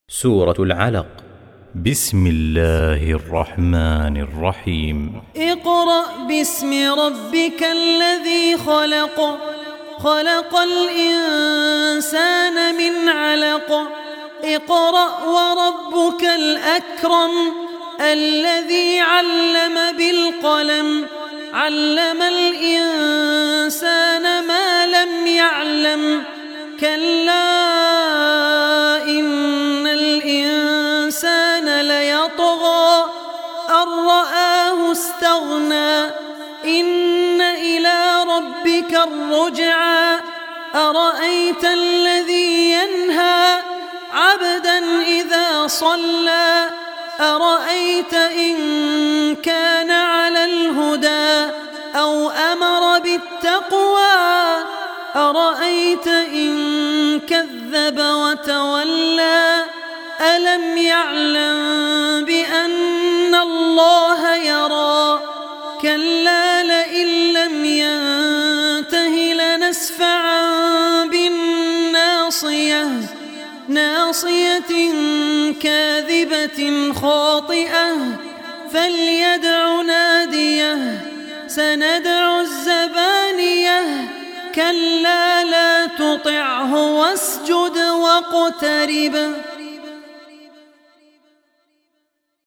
Surat Al Alaq Recitation by Abdul Rehman Al Ossi
Surat Al Alaq, listen online mp3 tilawat / recitation in the voice of Abdul Rehman Al Ossi.
96-surah-alaq.mp3